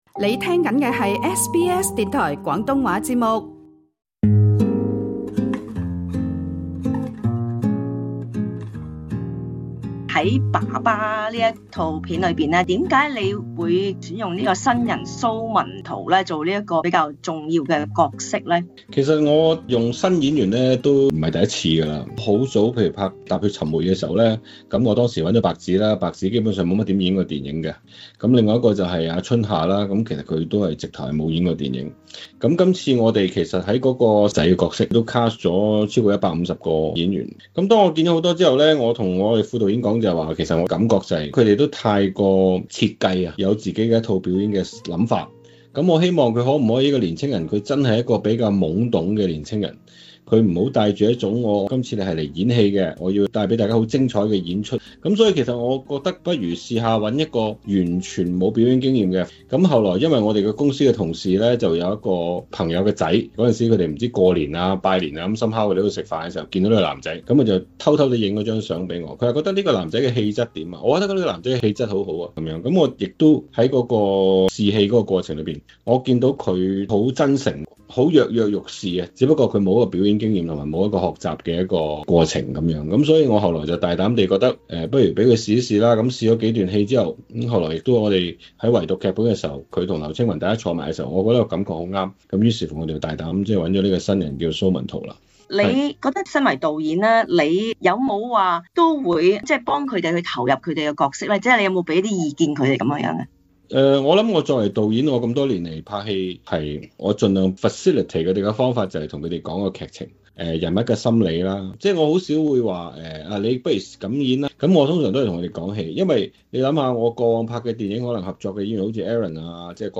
【專訪】勇奪香港電影三個主要獎項 「爸爸」導演翁子光指自己沒有創作才華？